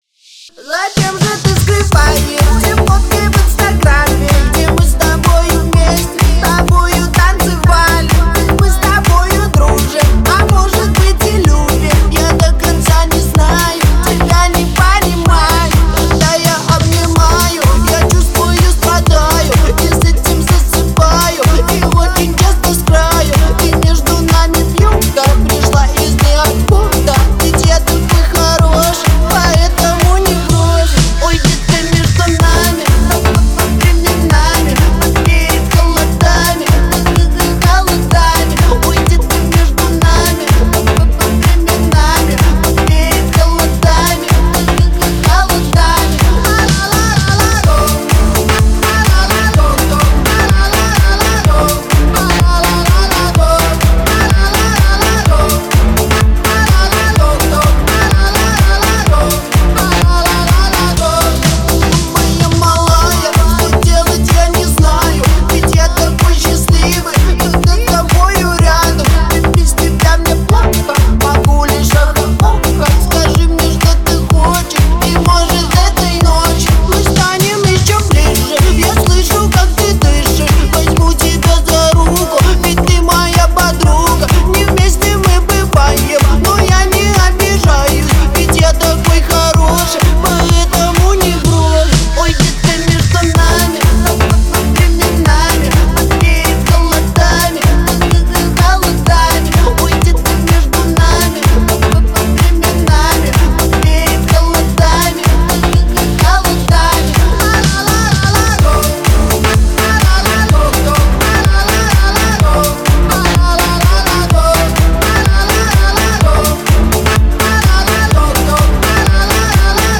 это яркий трек в жанре поп с элементами R&B